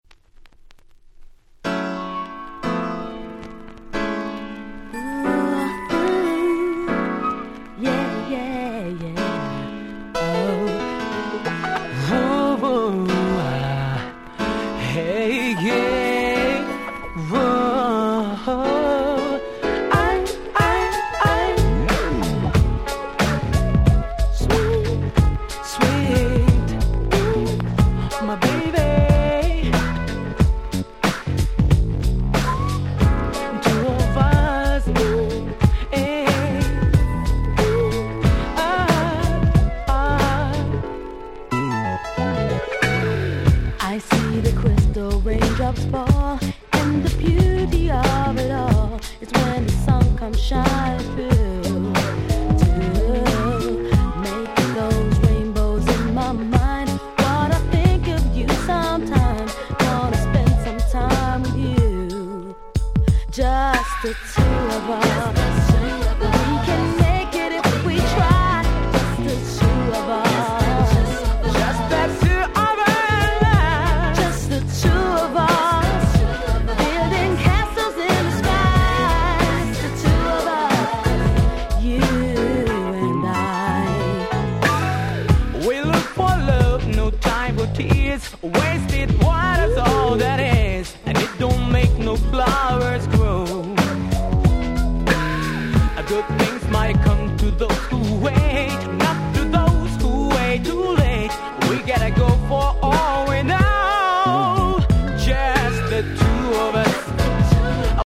【Media】Vinyl 12'' Single
95' Nice Cover R&B !!
J-Pop